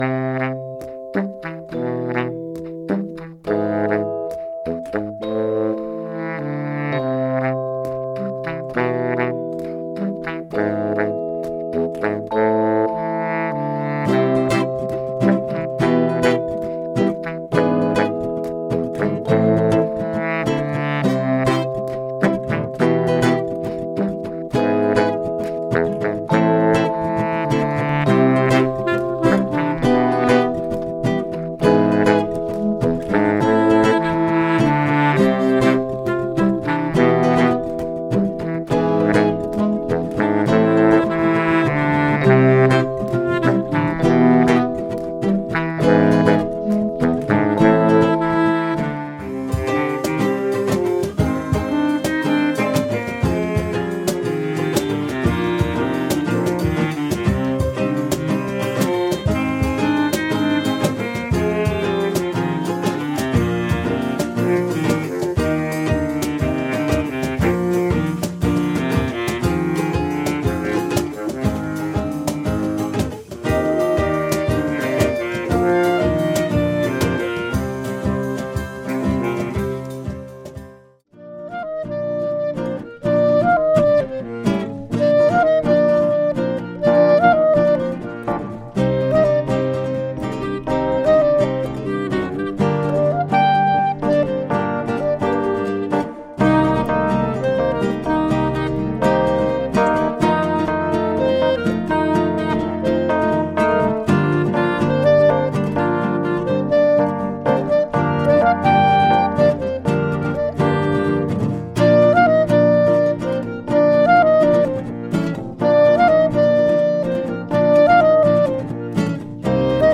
Voici quelques extraits de nos dernières répètes.